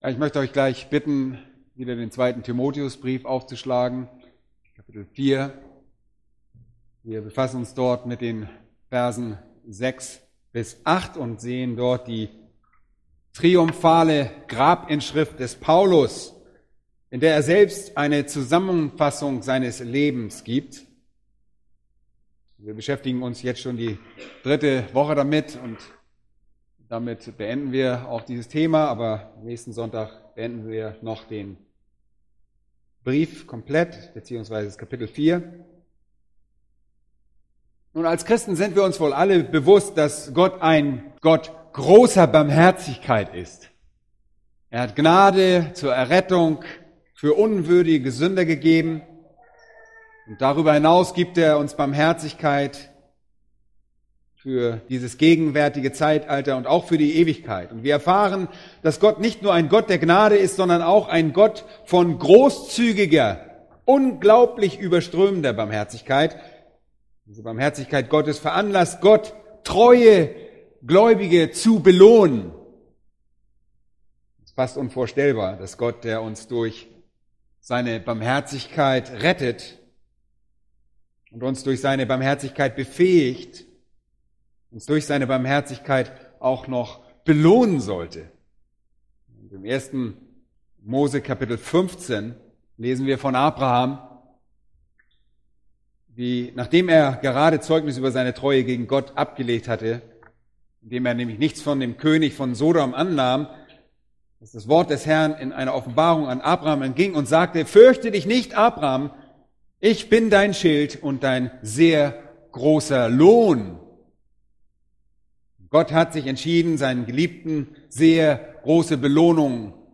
Überprüfe beim Hören dieser tiefgehenden Predigtserie deine Prioritäten und verbessere dein Vorbild zum Wohlgefallen Gottes.